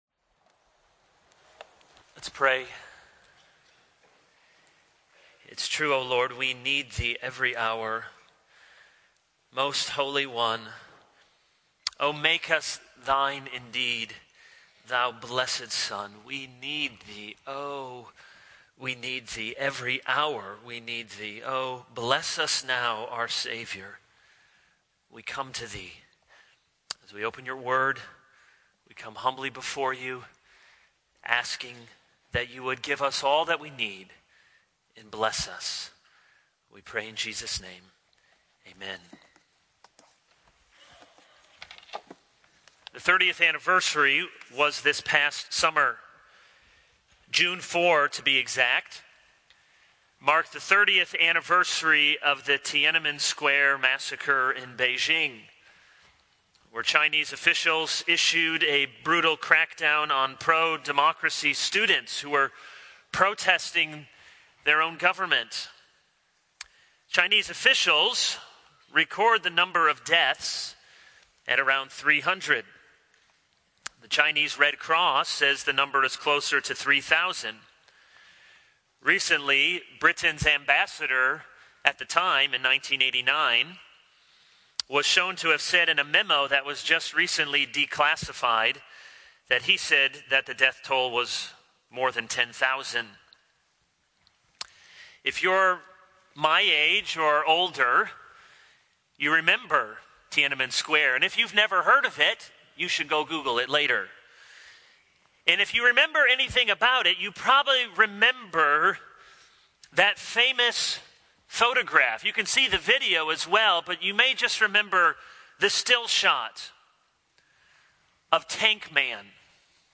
This is a sermon on Daniel 3.